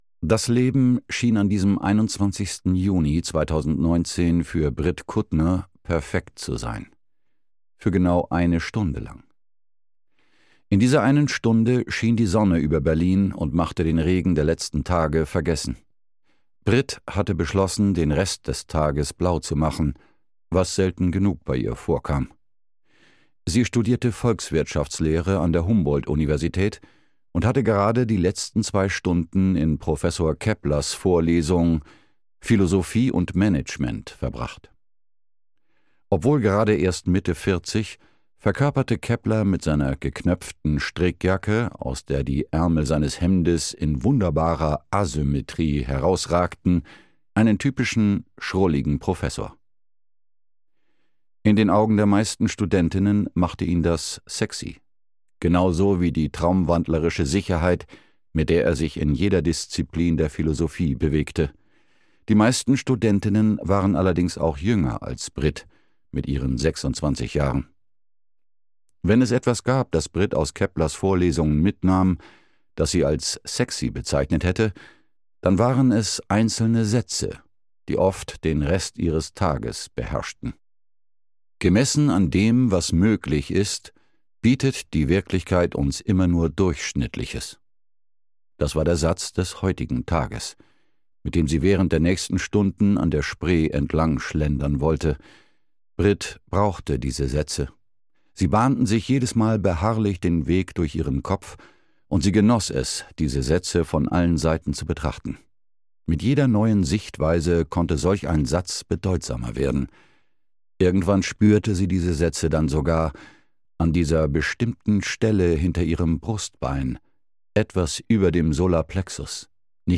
Earth – Die Verschwörung (Earth 1) - Hansjörg Thurn - Hörbuch